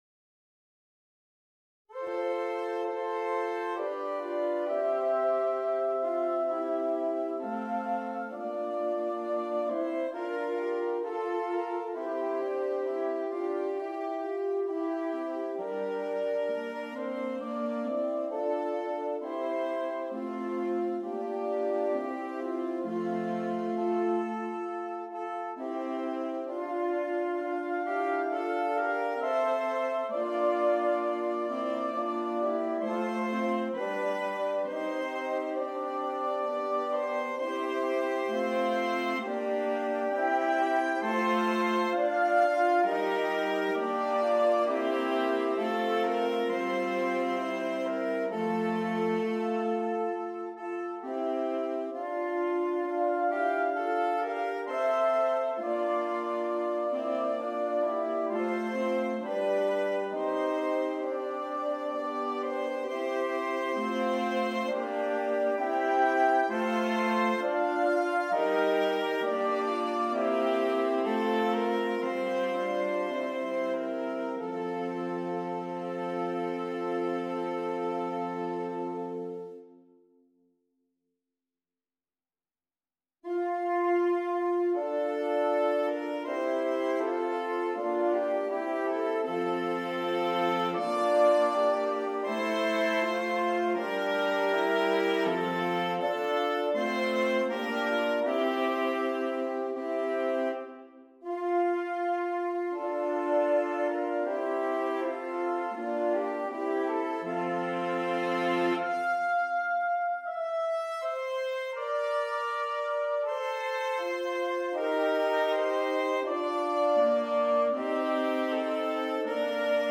4 Alto Saxophones